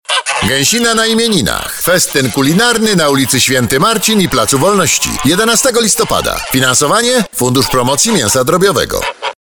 Spot radiowy